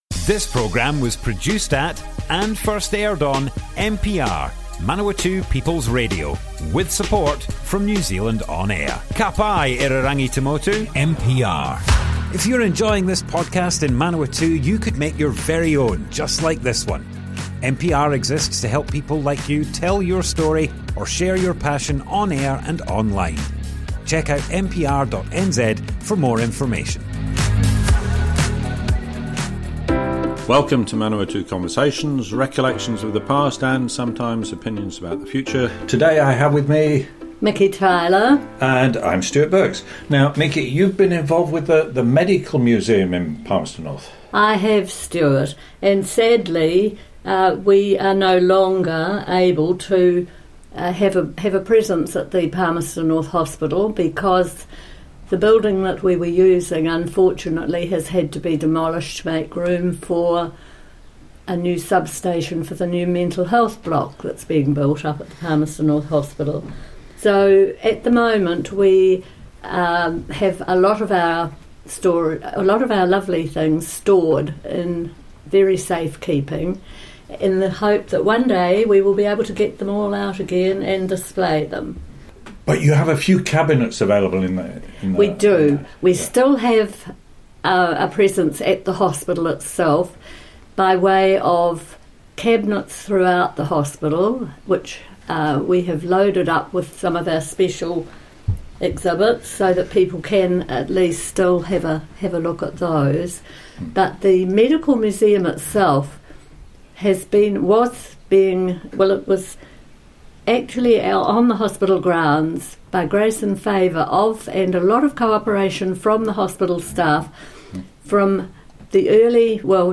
Manawatu Conversations More Info → Description Broadcast on Manawatu People's Radio, 3rd December 2024.
oral history